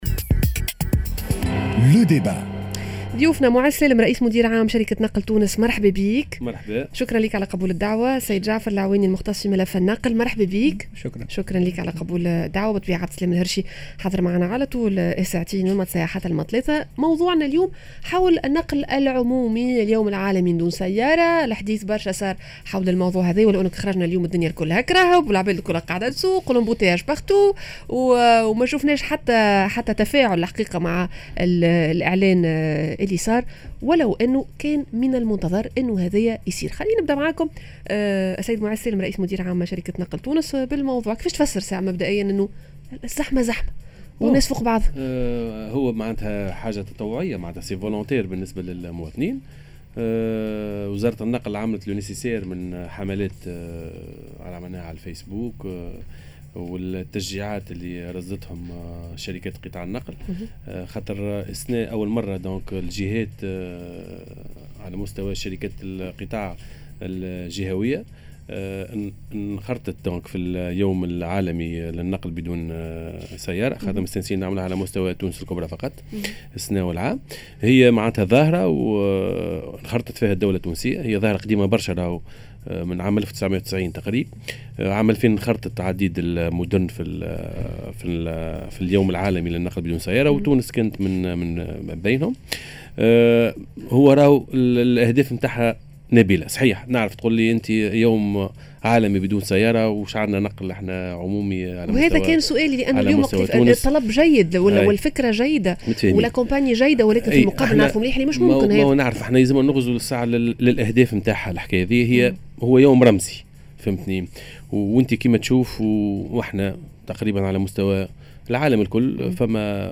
Le débat: انّجموا اليوم نقولوا عنّا نقل عمومي في بلادنا؟